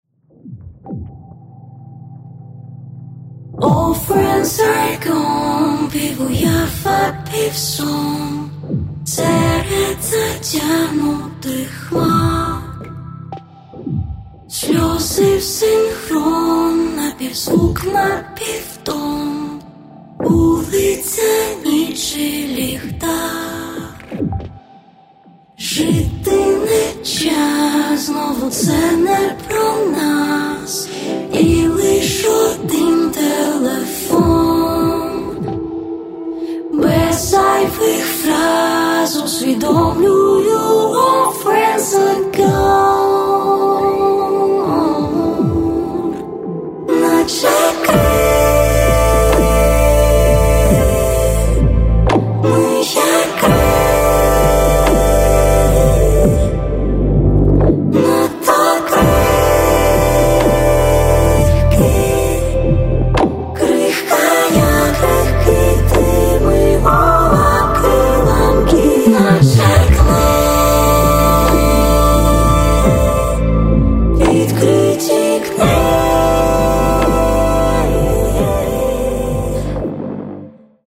Поп (Легкая)
Каталог -> Поп (Легкая) -> Лирическая
electro-folk